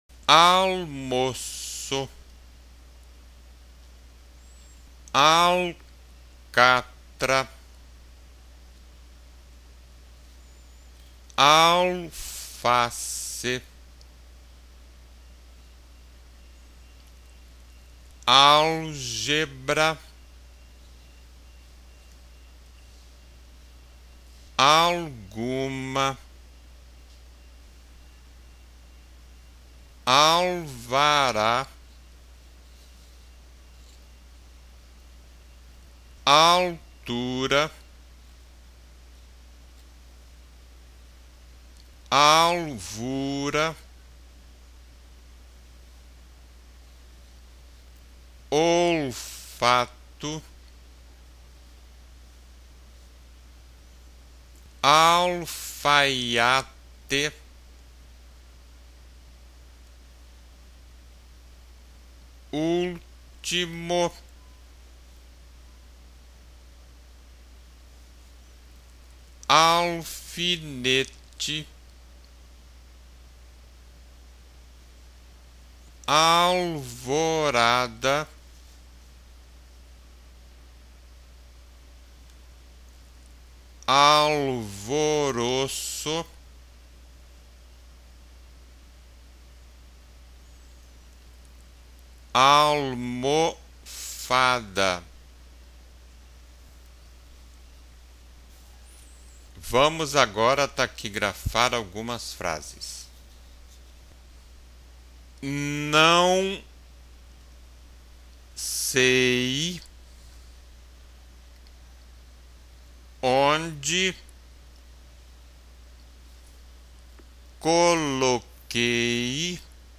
Ditado para escutar e taquigrafar.
ditado_20.wma